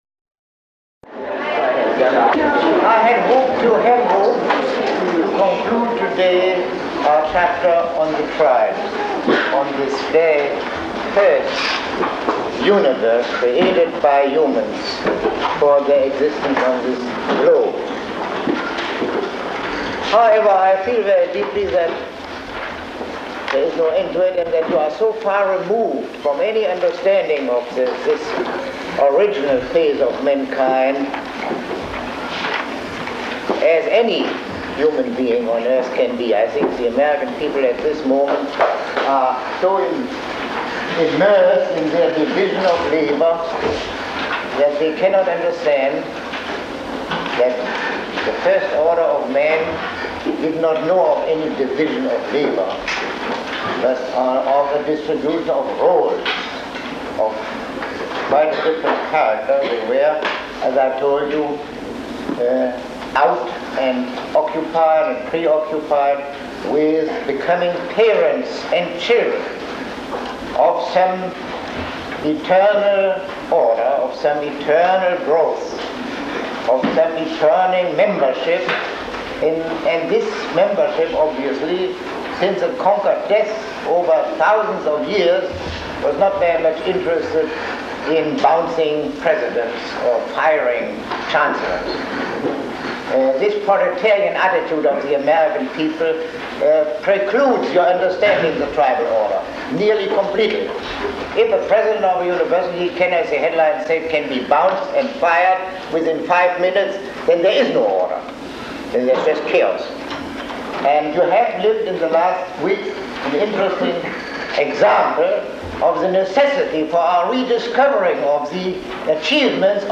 Lecture 06